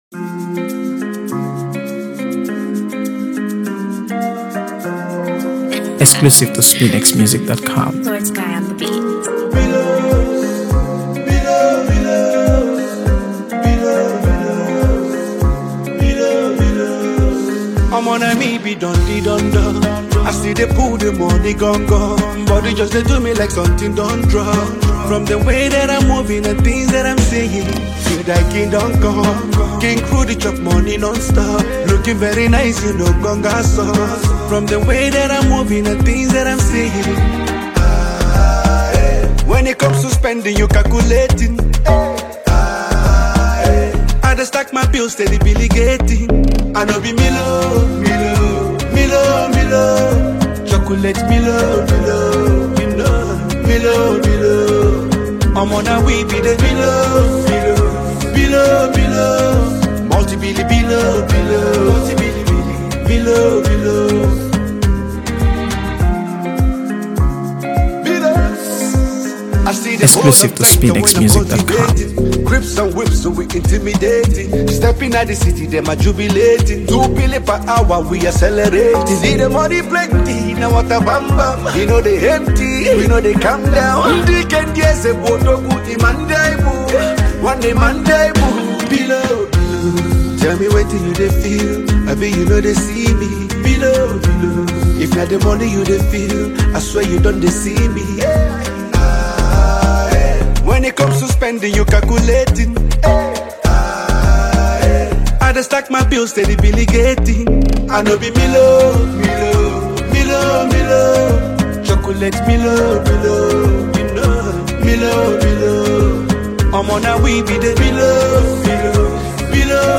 AfroBeats | AfroBeats songs
The track opens with a hypnotic, chant-like hook